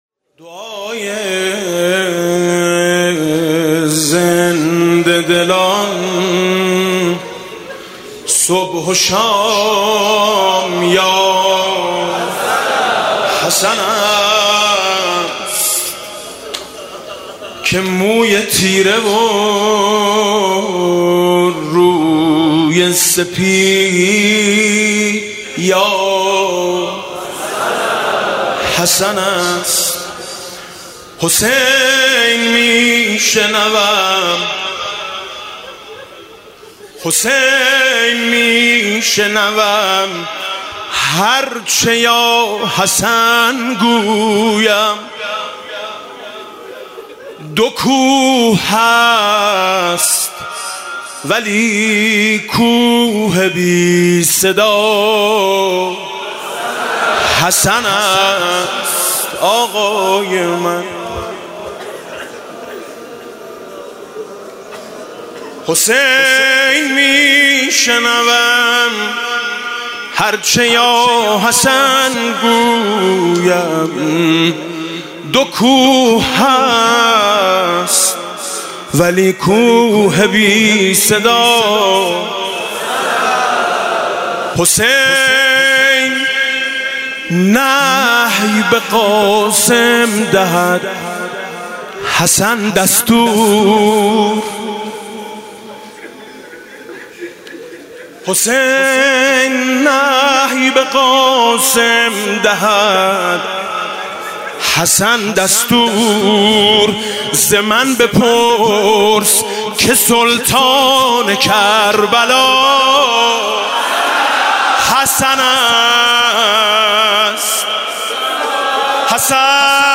«محرم 1396» (شب پنجم) مدح: دعای زنده دلان صبح و شام یا حسن است